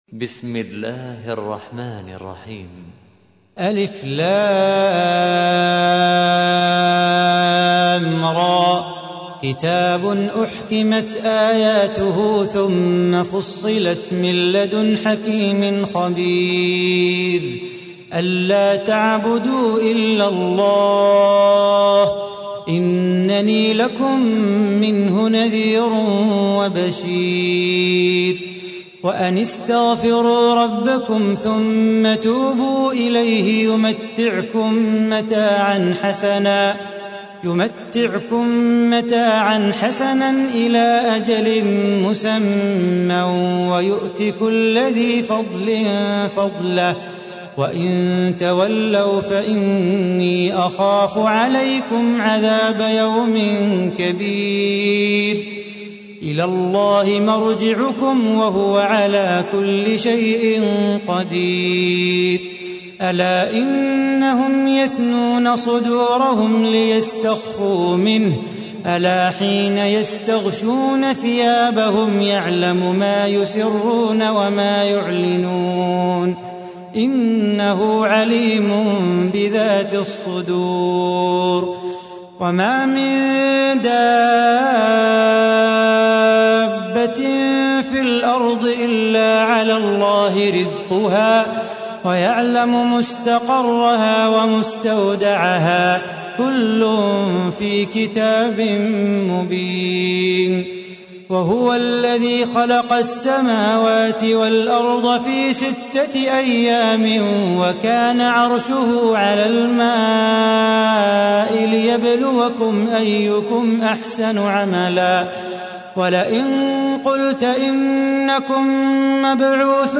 تحميل : 11. سورة هود / القارئ صلاح بو خاطر / القرآن الكريم / موقع يا حسين